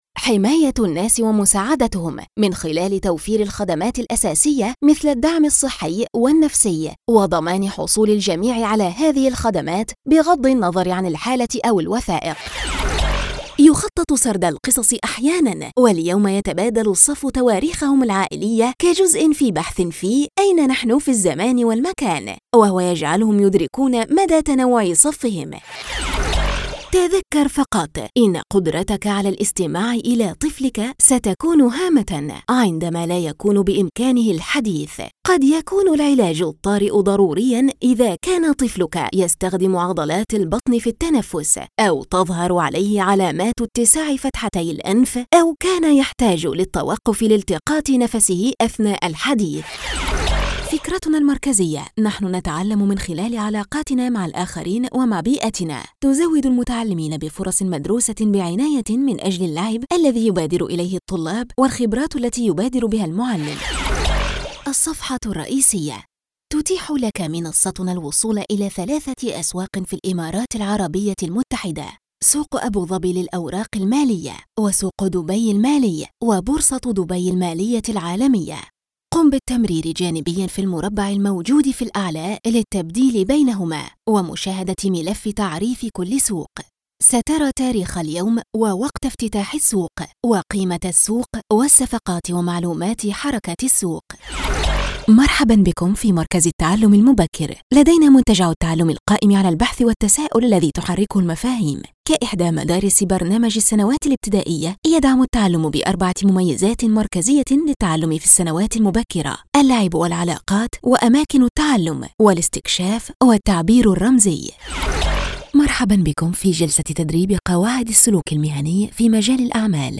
Female
Devine, versatile, Calm, Relax, emotional, authentic, deep, warm, Motivation
E-Learning
All our voice actors have professional broadcast quality recording studios.
1102Arabic_E-Learning_DemoReel.mp3